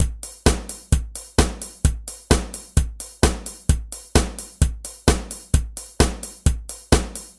直升机引擎发动
Tag: 直升机 实地录音 引擎 飞机 平面 内部